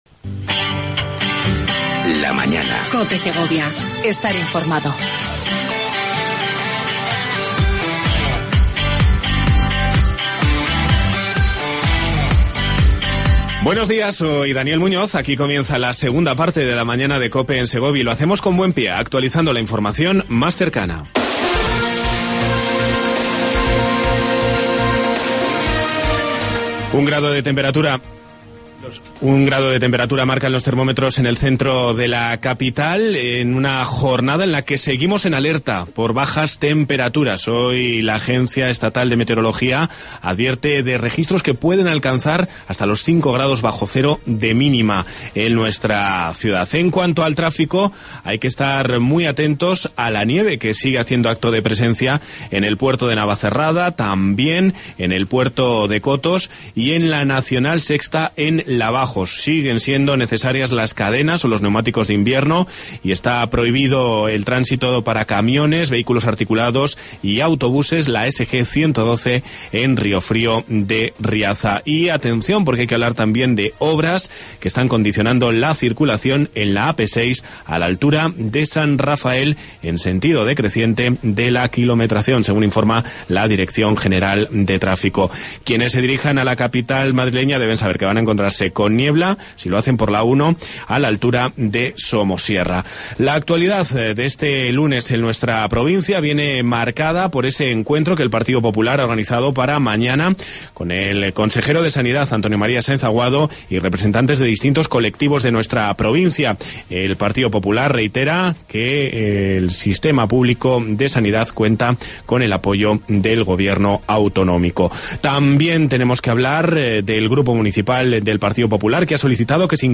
AUDIO: Entrevista a La Subdelegada del Gobierno en Segovia, Pilar Sanz.